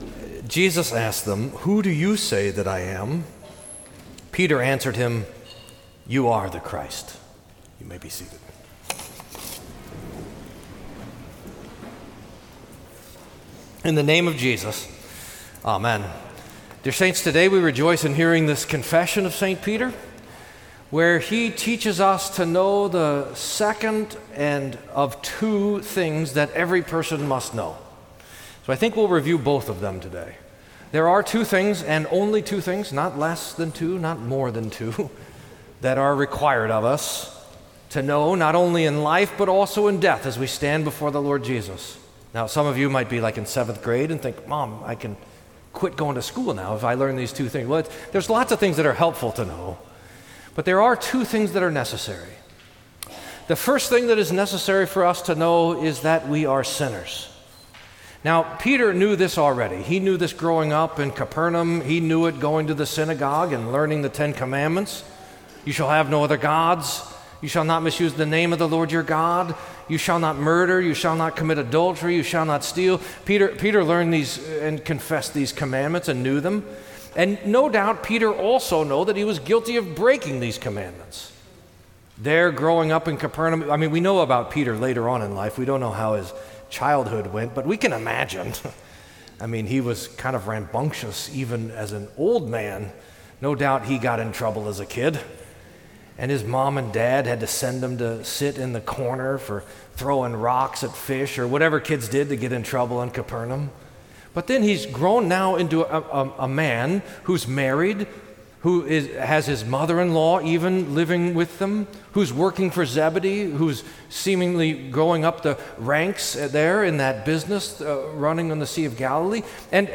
Sermon for the Confession of St Peter